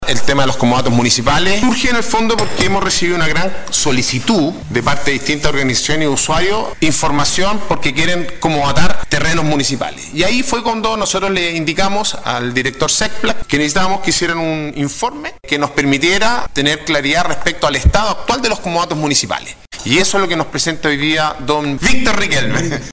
En el marco de lo que se conoce como “mensaje del Alcalde”, en el más reciente Concejo Municipal de Pucón, sesión N°35, se conoció el dramático estado en el que se encuentran los comodatos de terrenos públicos de la comuna, confirmándose un evidente e histórico estado de abandono de sitios que debieran tener una óptima utilización para beneficio de los habitantes de la urbe lacustre.
Alcalde-Sebastian-Alvarez-explica-el-origen-del-informe-por-Comodatos-.mp3